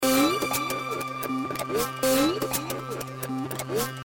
Play, download and share diiiiiing original sound button!!!!
ding-1_a8XfM0z.mp3